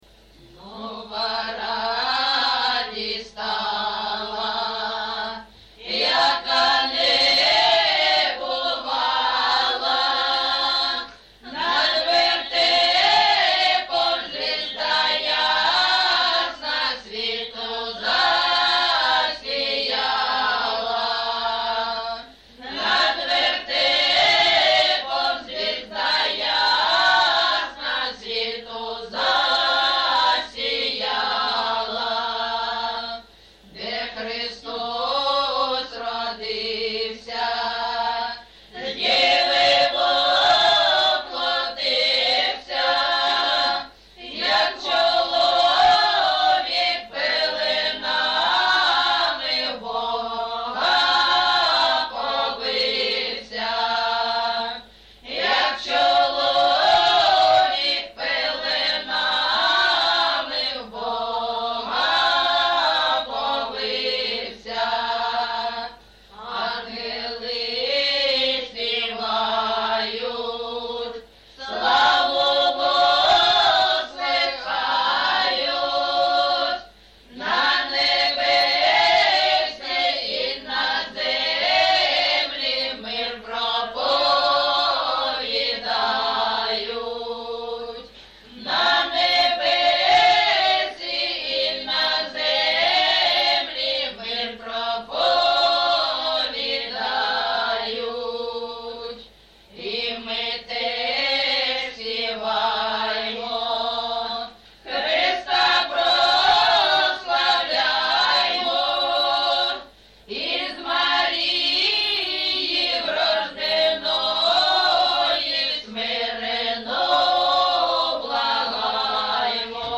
ЖанрКолядки, Сучасні пісні та новотвори
Місце записус-ще Щербинівка, Бахмутський район, Донецька обл., Україна, Слобожанщина